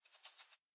minecraft / sounds / mob / rabbit / idle4.ogg